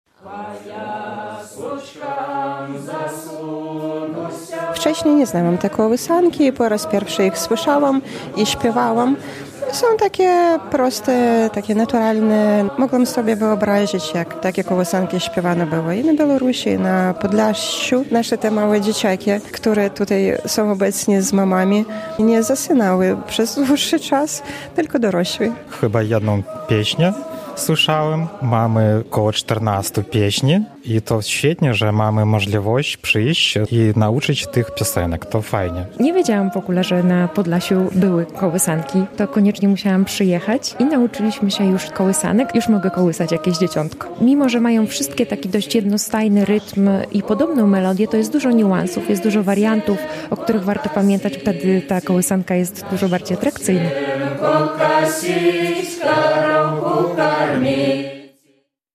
Tradycyjne podlaskie kołysanki na warsztatach śpiewu